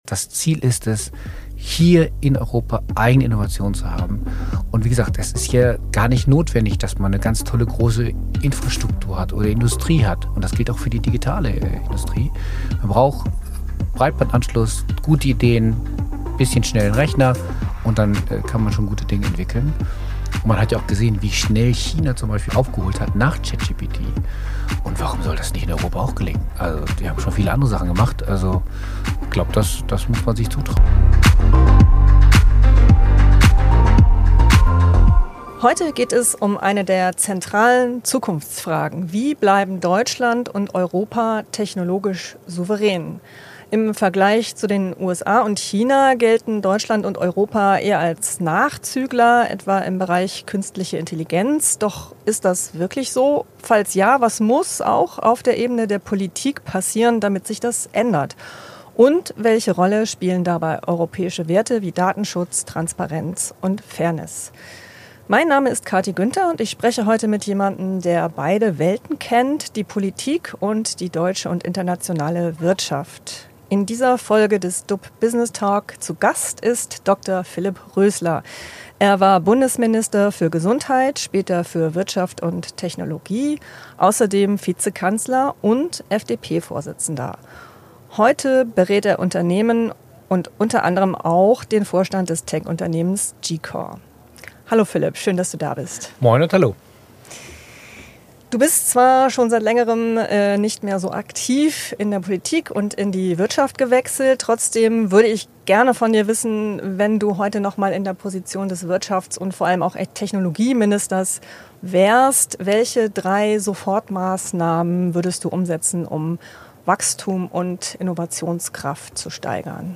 Unser Gast weiß, wie Macht und Märkte funktionieren: Dr. Philipp Rösler, früher Bundesminister für Gesundheit, später für Wirtschaft und Technologie, Vizekanzler und FDP-Vorsitzender, ist seit 2013 in der internationalen Wirtschaft unterwegs.